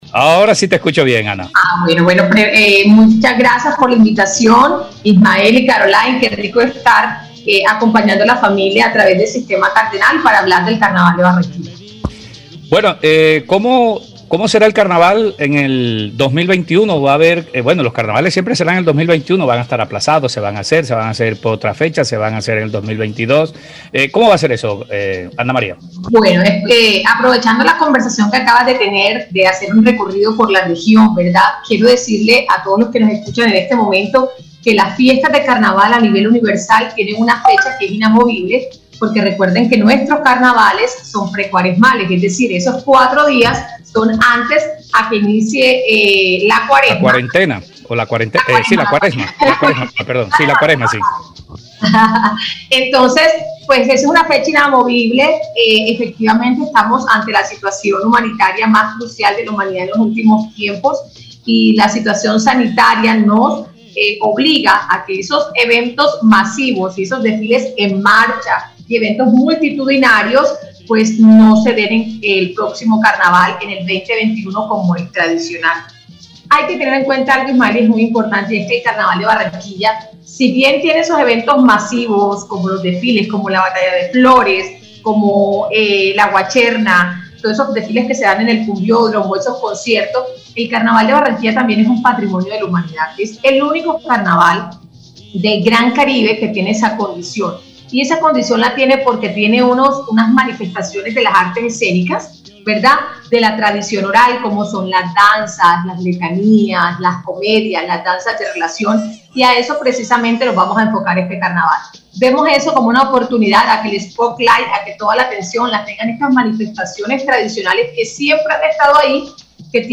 en conversación con el programa Cuarentena del Sistema Cardenal